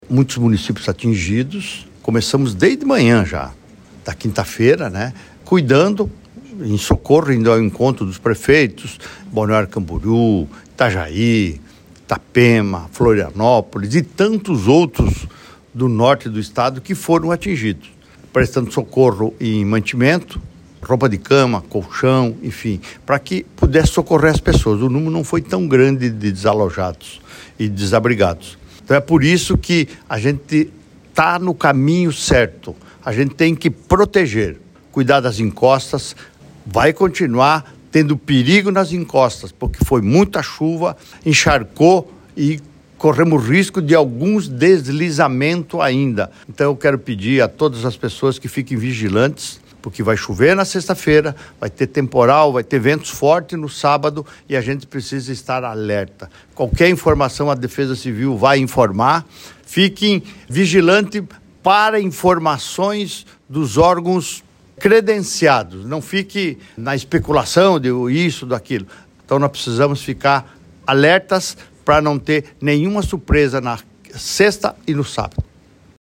SECOM-Sonora-governador-Avaliacao-chuvas-em-SC.mp3